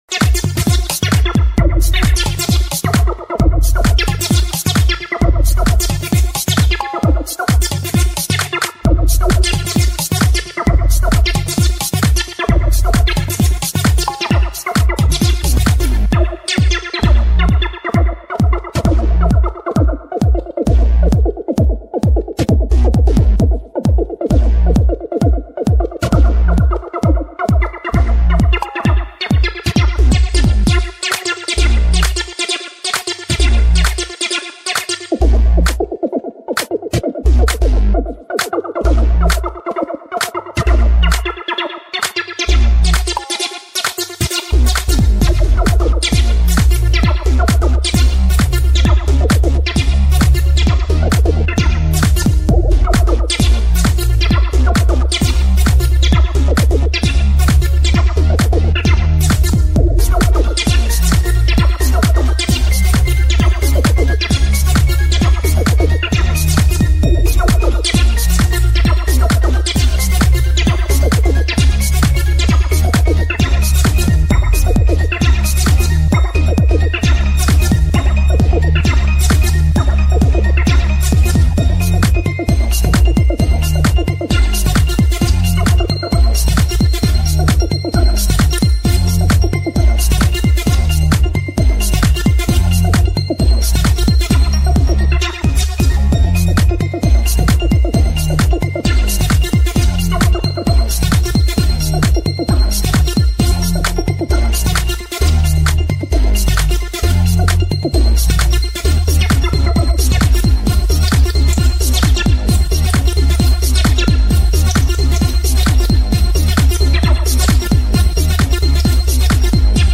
AfroTechnocology sounds
Vibe Detroit Techno.